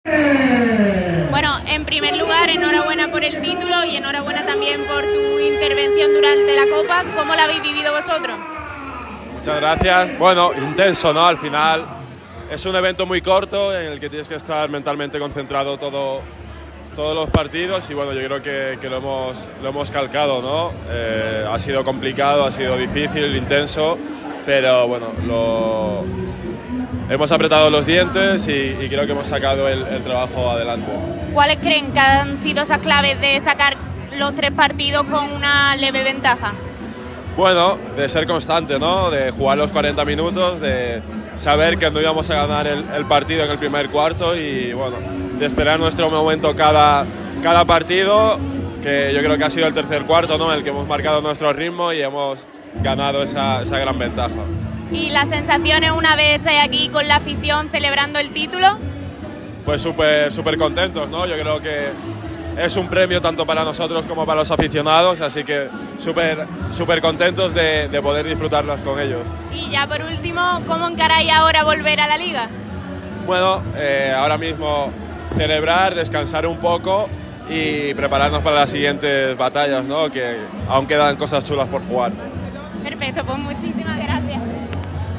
Durante la visita a Unicaja Banco, Radio MARCA Málaga ha tenido la oportunidad de charlar con Olek Balcerowski y Tyson Pérez.
TYSON-PEREZ-EN-LA-FIESTA-DE-LA-COPA.mp3